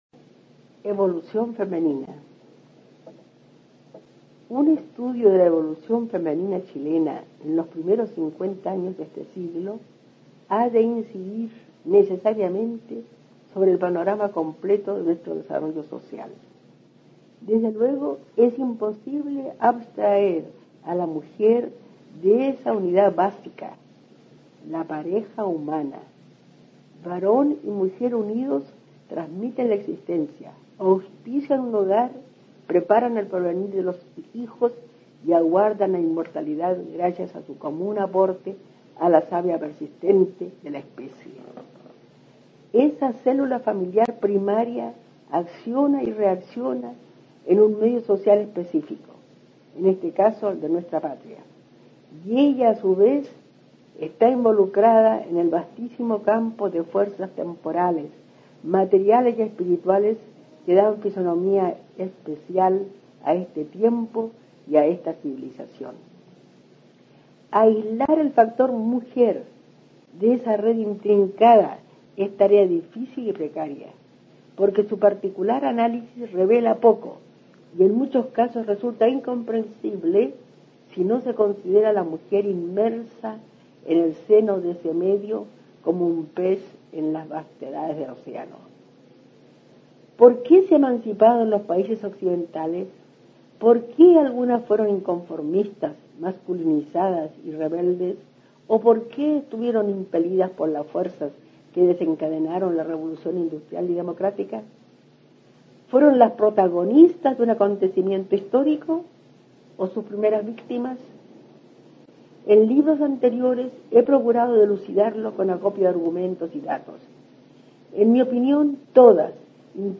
Aquí se puede escuchar a la destacada profesora chilena Amanda Labarca (1886-1975) leyendo su ensayo Evolución femenina.